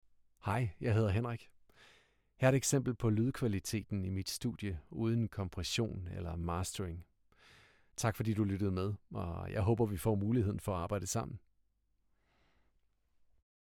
Male
Approachable, Character, Conversational, Corporate, Energetic, Engaging, Friendly, Gravitas, Natural, Reassuring, Sarcastic, Soft, Upbeat, Versatile, Warm
Rigsdansk (native), Jysk, Københavnsk
Commercial medley.mp3
Microphone: Neumann u87